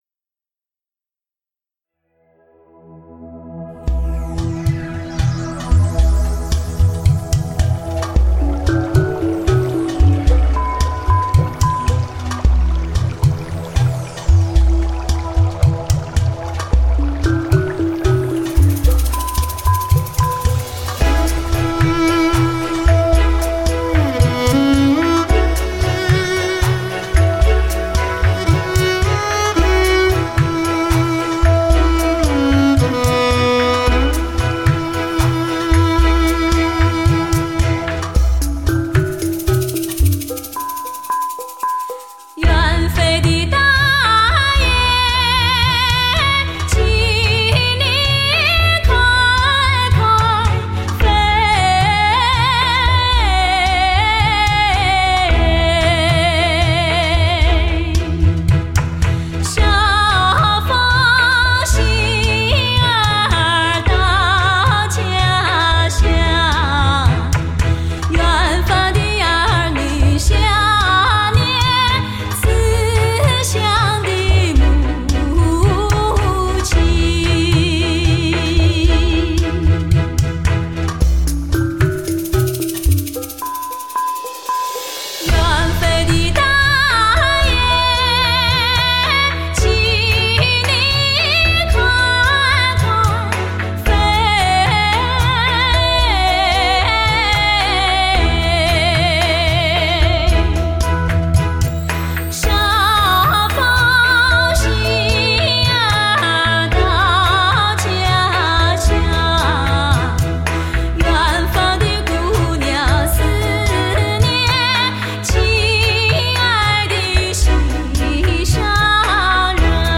来自广袤高原上广为传唱的绝美天籁之声
悠远厚重的民族情怀，生动鲜活的音乐内涵
极致发烧的独特魅力唱腔，演绎最具发烧魅力的高原金曲